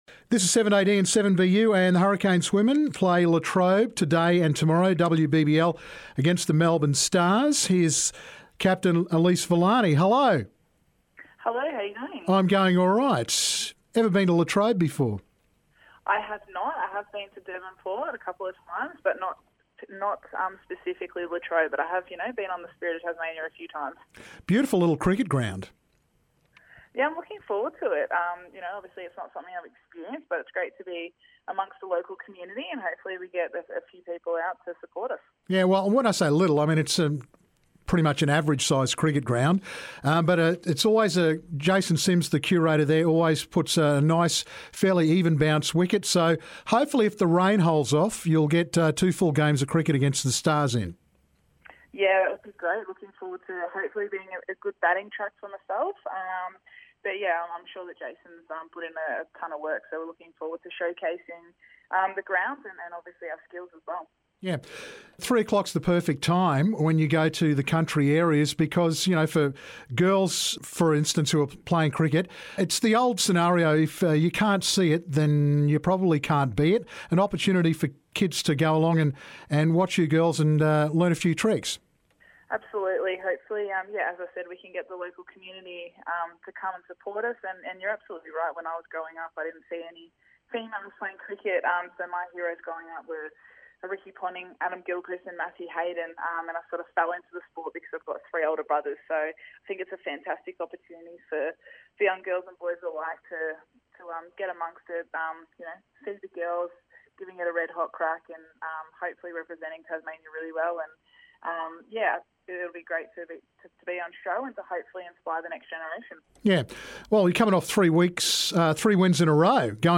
The Hurricanes play the Melbourne Stars at Latrobe today and tomorrow. Here's Hurricanes Captain Elyse Villani.